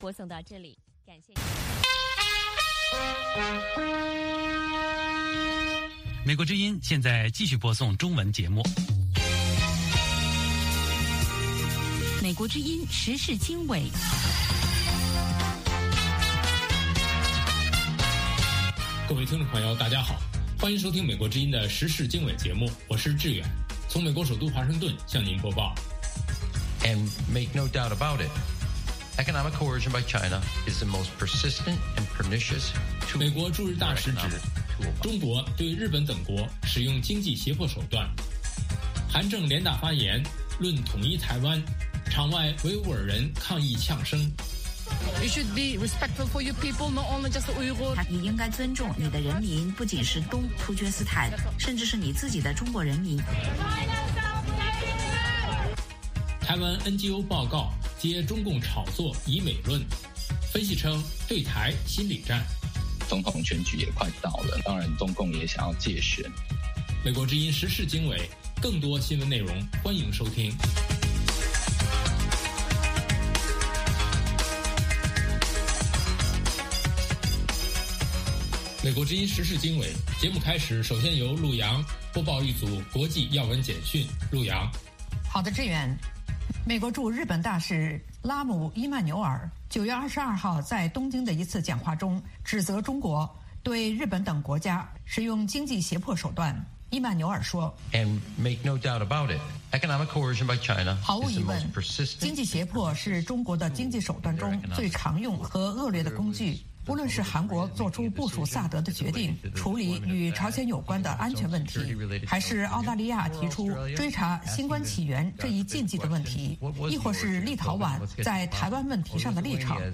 英语教学